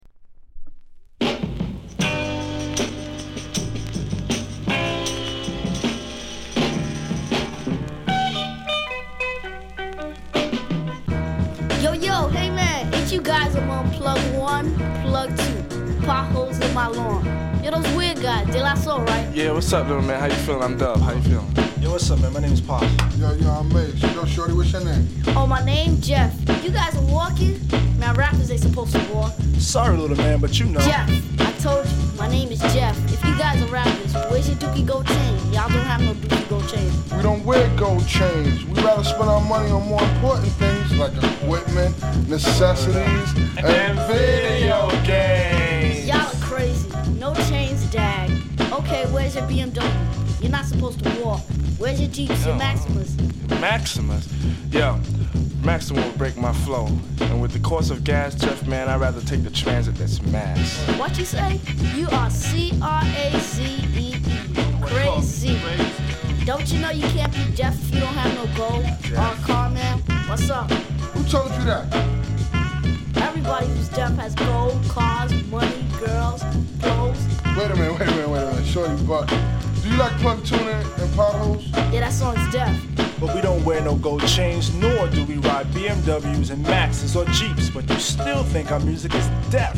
類別 饒舌、嘻哈